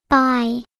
bye.ogg